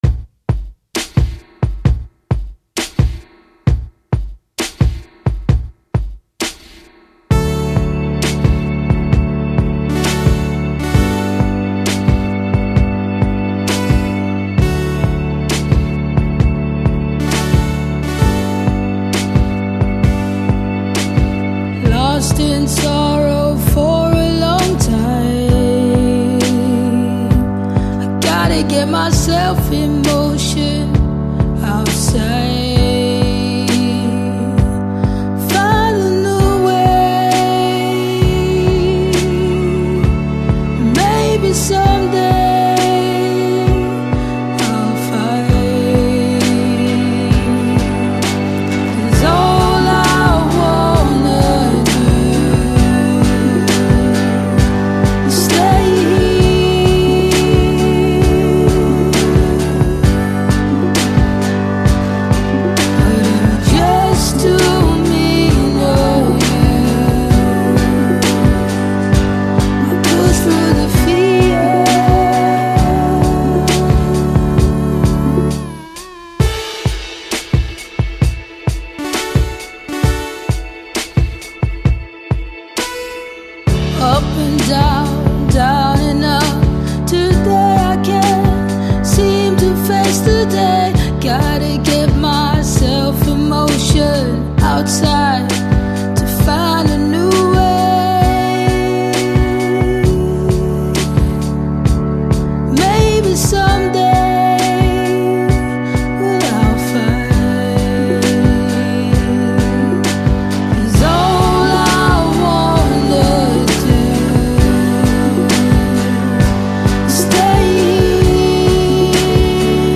Alt-folk singer-songwriter